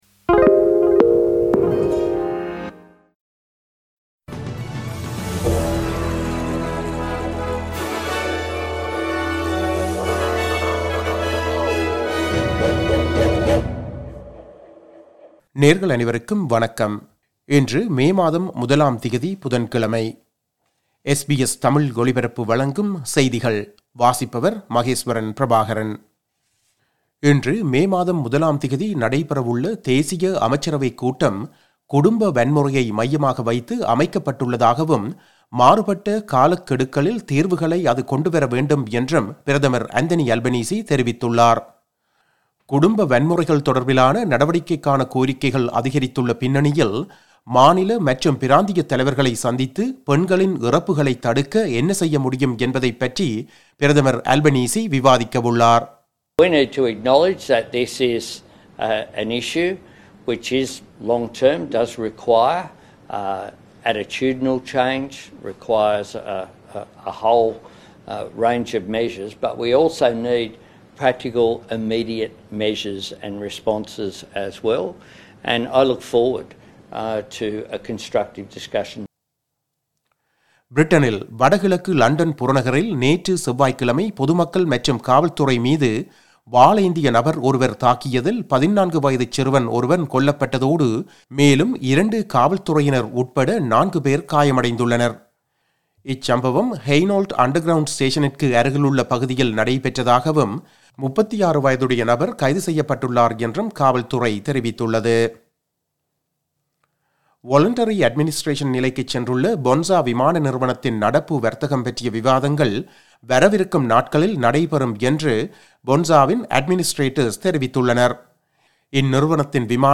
SBS தமிழ் ஒலிபரப்பின் இன்றைய (புதன்கிழமை 01/05/2024) செய்திகள்.